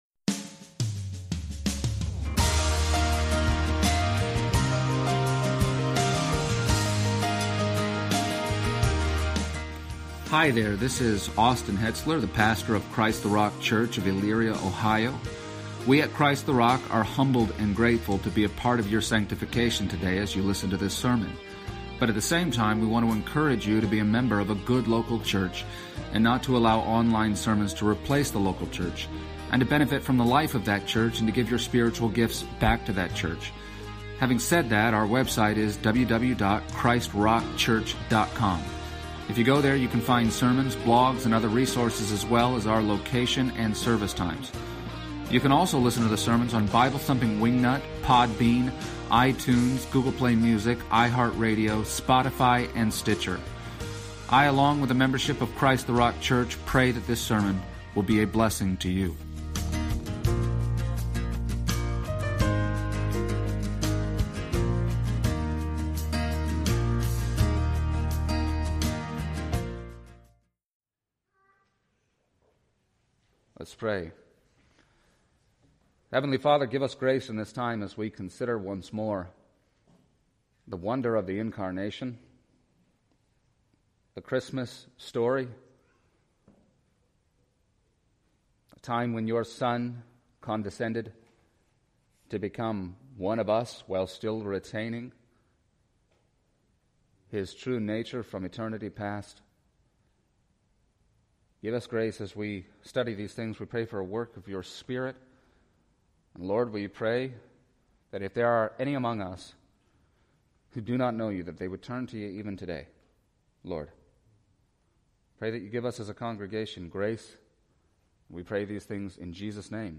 Sunday Service Passage: Luke 2:1-20 Service Type: Sunday Morning %todo_render% « Stop Staring at the Sky…